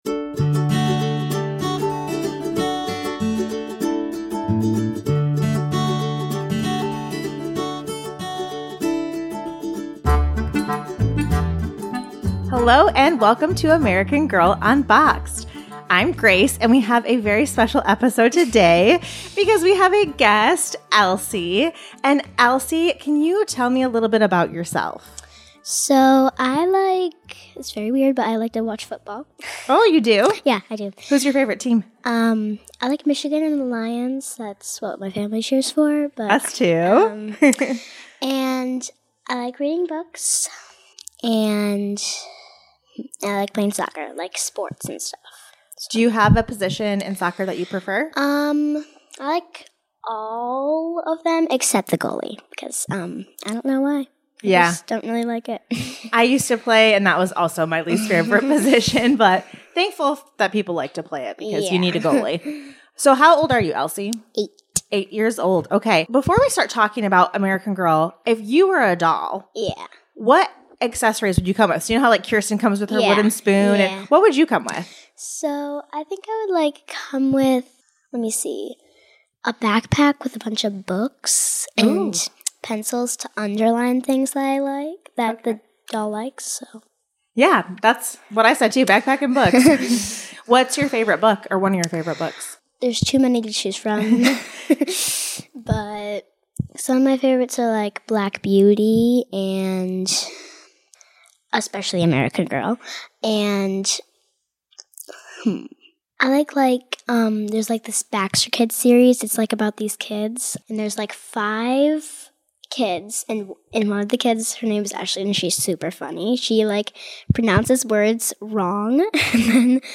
Or do you really just like nostalgic stories told in Midwestern accents?